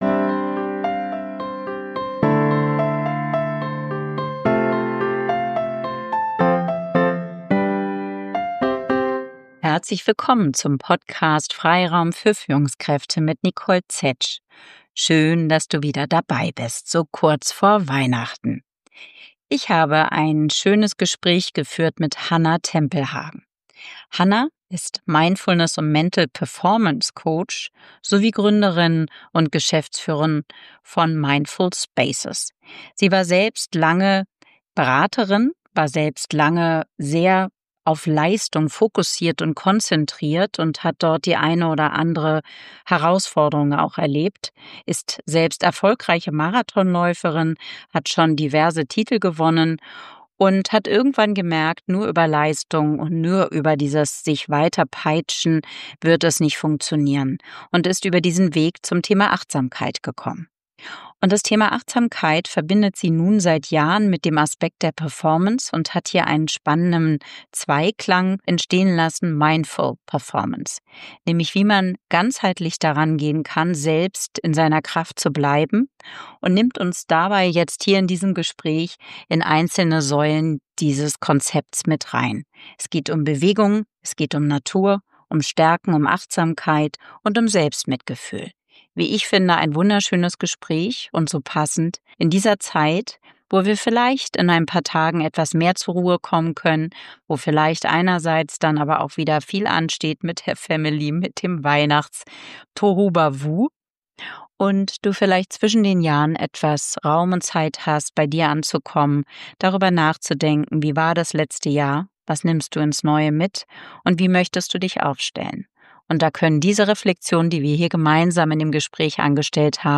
#82 Mindful Performance – Highperformance gesund und nachhaltig, ein Gespräch